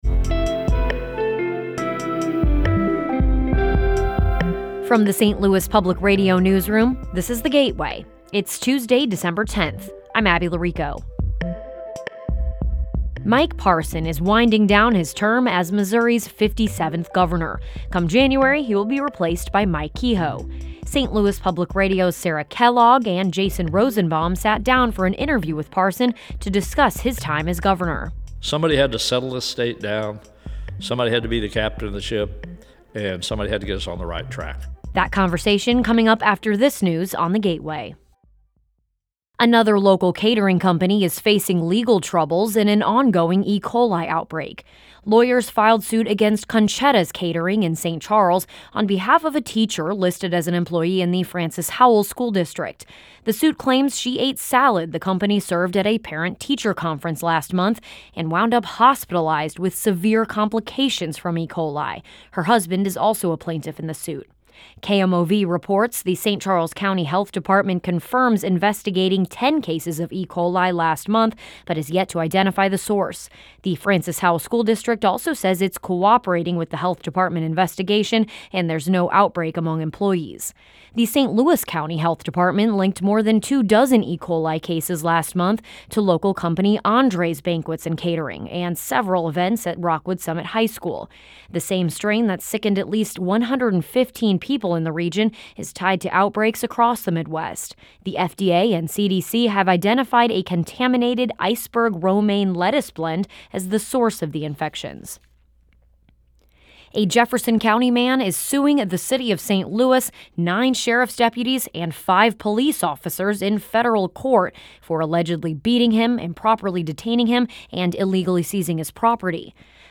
sat down for an interview with Parson to discuss his time as governor.